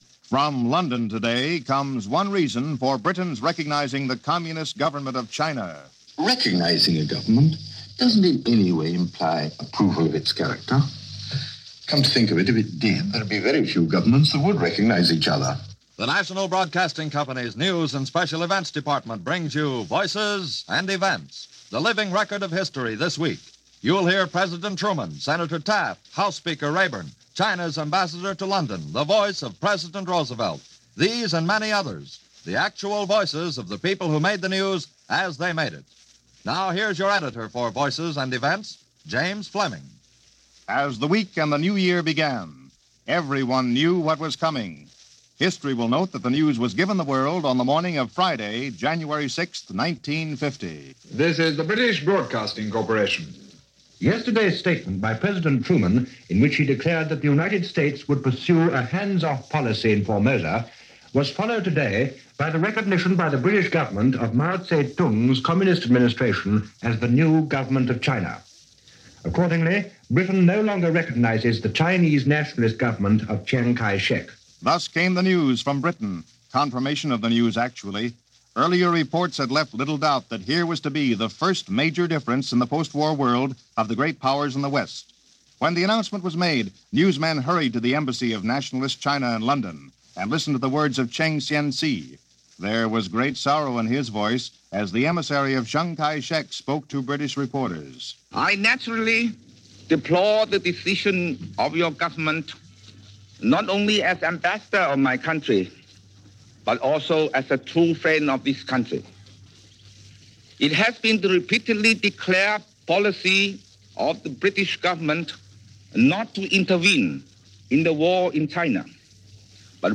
January 7, 1950 - Recognizing Mao - Indicting Influence Peddlers - Reconvening Congress - news for the week, ending January 7th.